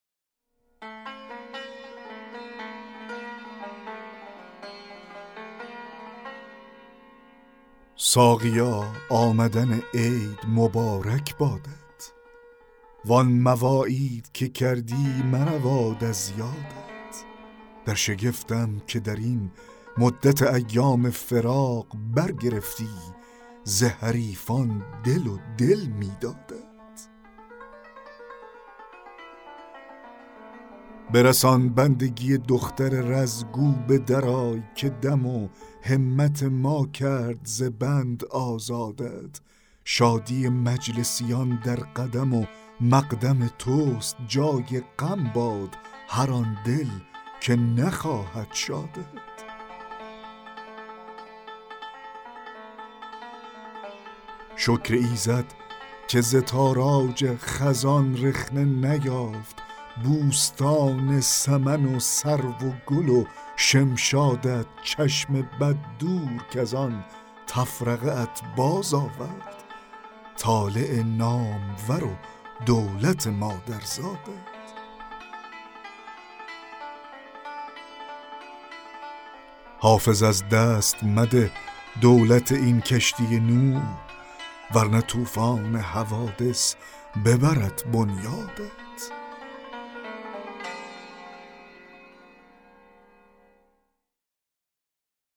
دکلمه غزل 18 حافظ
دکلمه غزل ساقیا آمدن عید مبارک بادت
دکلمه-غزل-18-حافظ-ساقیا-آمدن-عید-مبارک-بادت.mp3